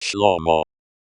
ˈʃlo̞.mo̞(h)/; lit. “peaceful”; English /ˈsɒ.lə.mən/) was a king of Israel, best known for his appearances in the Hebrew Bible.
shlomo.opus